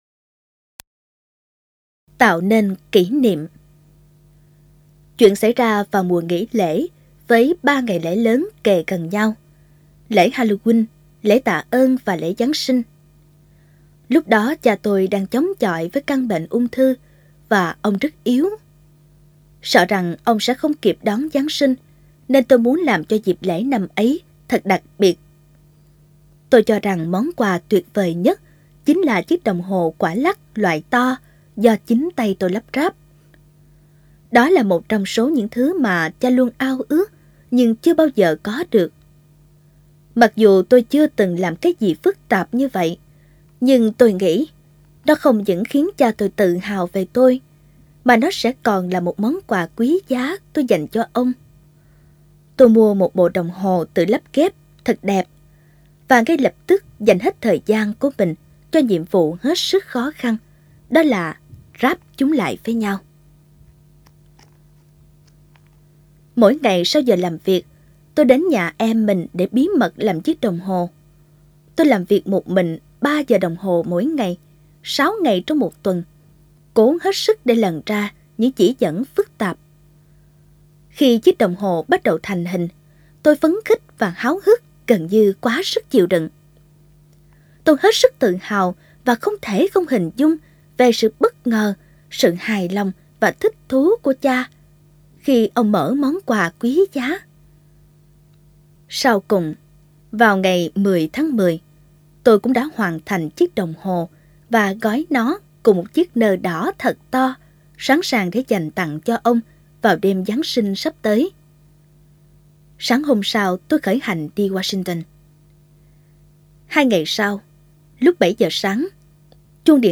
Sách nói | Tạo nên kỷ niệm